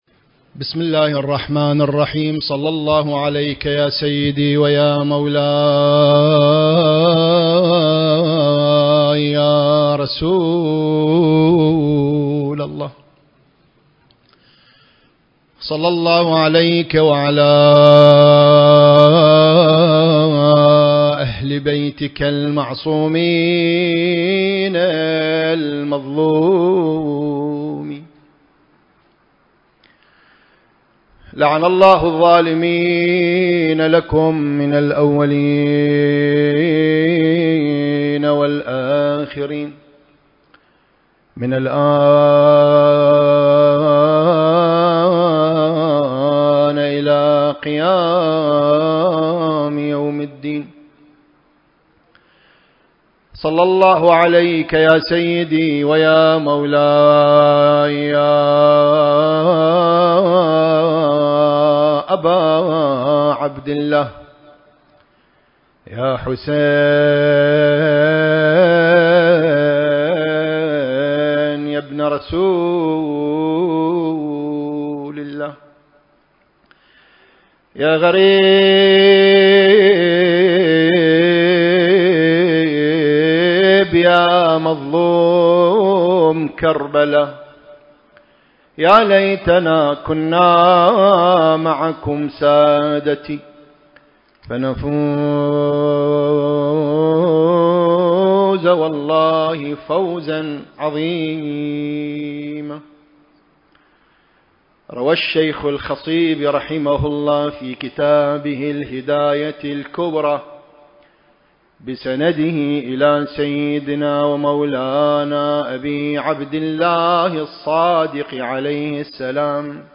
سلسلة: عقيدتنا في الإمام المهدي (عجّل الله فرجه) وأثر ذلك (2) المكان: موكب النجف الأشرف/ قم المقدسة التاريخ: 1444 للهجرة